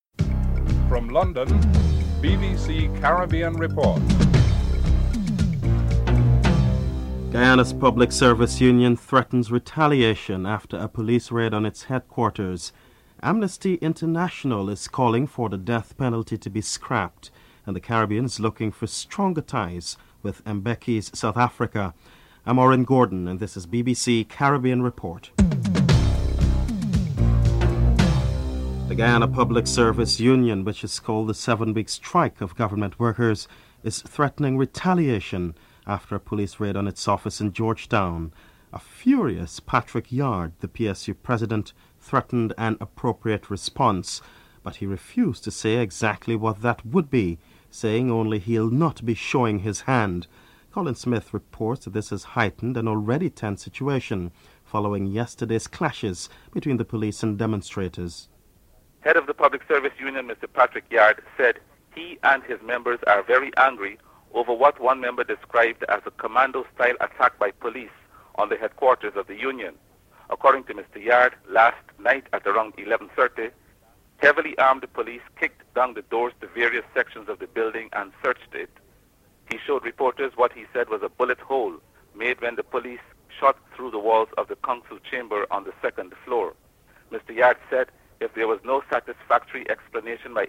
In his acceptance speech Mr. Mbeki reflects on the significance of his election as President.
Dominica Prime Minister Edison James discusses CARICOM economic and cultural ties with South Africa (09:04 - 14:10)